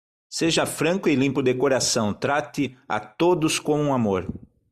Pronounced as (IPA)
/ˈfɾɐ̃.ku/